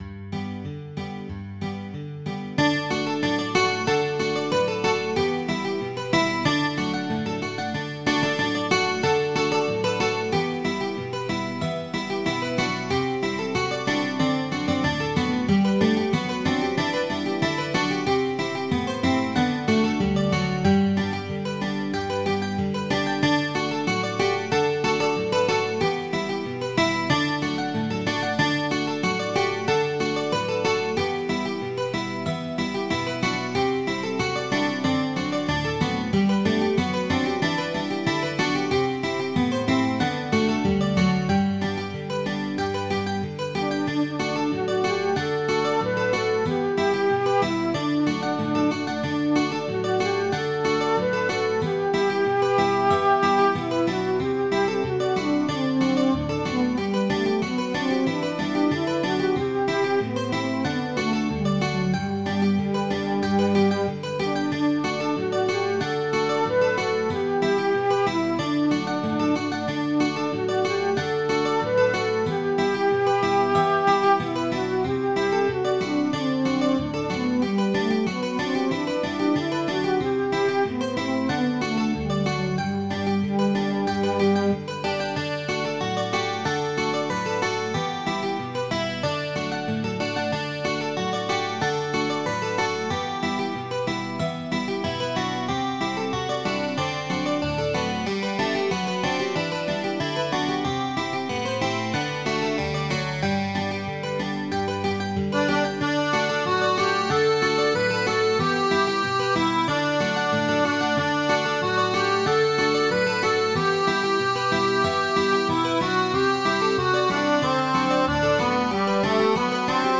ghost.mid.ogg